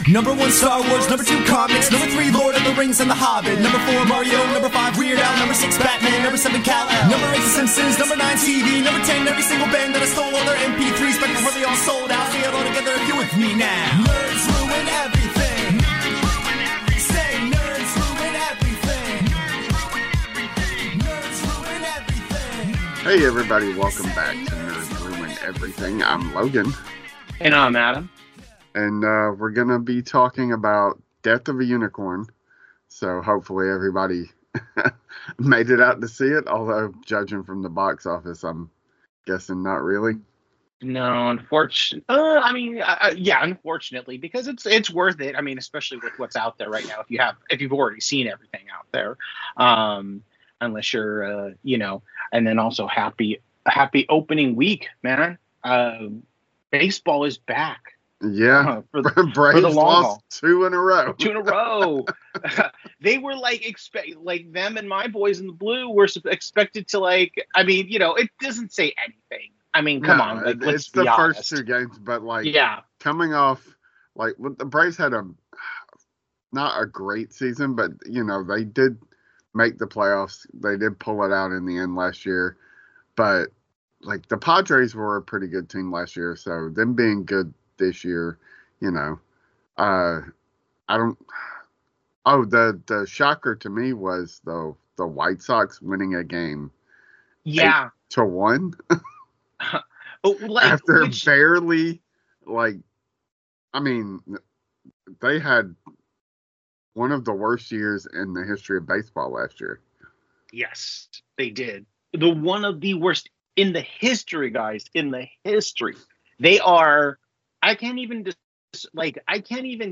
two nerds